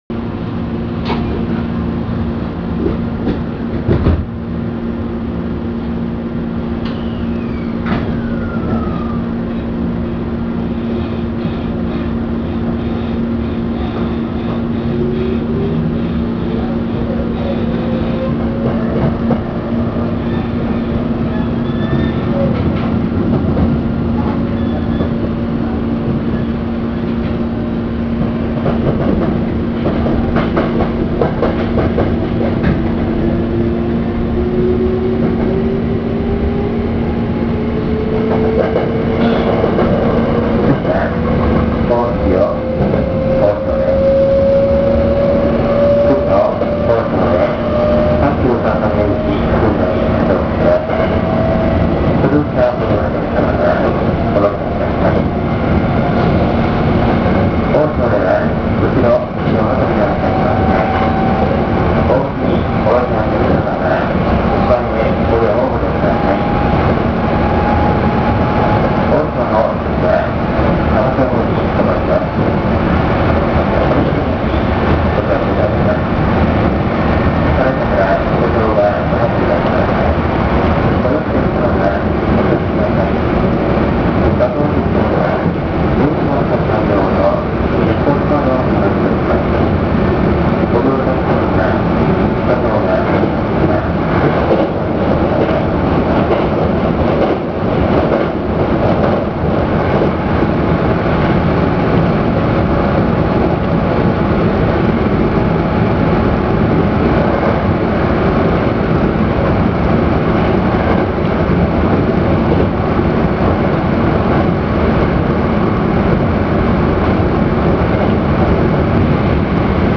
・5000系走行音
【本線】飾磨→大塩（6分4秒：5.93MB）